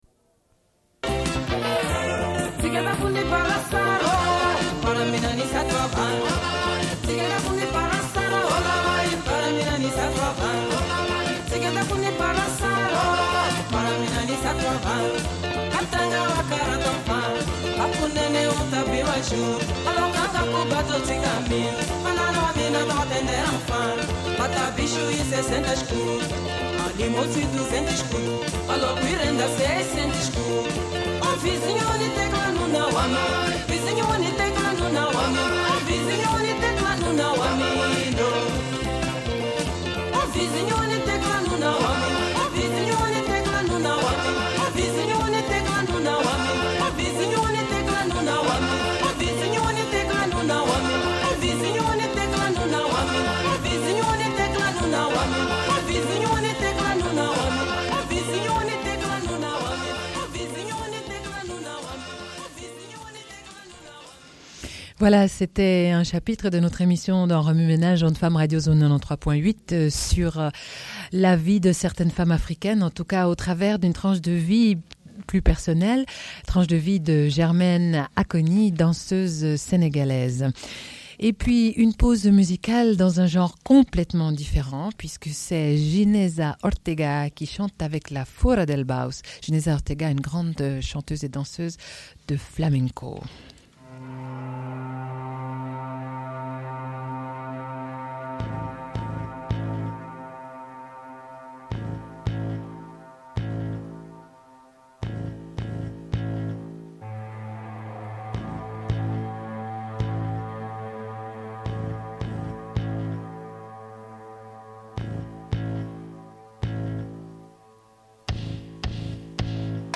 Une cassette audio, face B29:20